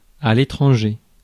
Ääntäminen
France: IPA: [e.tʁɑ̃.ʒe]